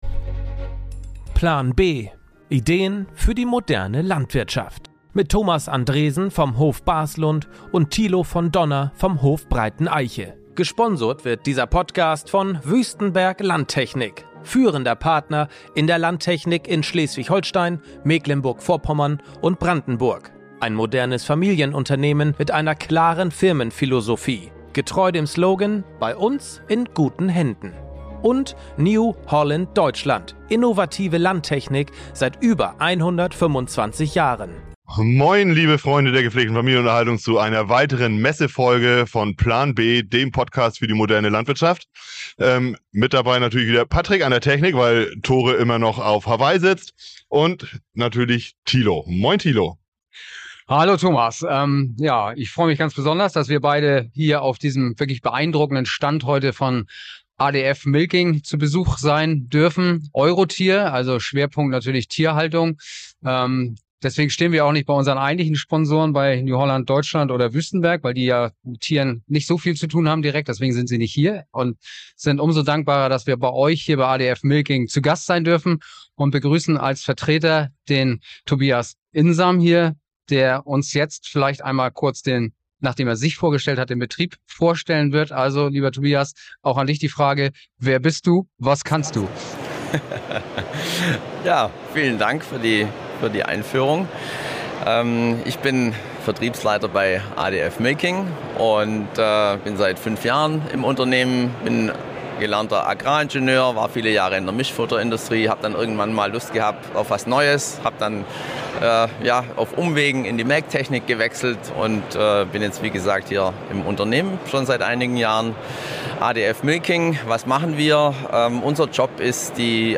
#31: ADF Milking - Live von der EuroTier 2024 ~ Plan B - Ideen für die moderne Landwirtschaft Podcast
Beschreibung vor 1 Jahr In dieser Folge von der EuroTier geht es um Eutergesundheit und Hygiene im Stall. Welchen Beitrag leistet ADF in diesem so wichtigen Bereich der Milchviehhaltung und wie hat das ADF System zur Steigerung der Milchmenge um 50% auf dem Q-Hof beigetragen?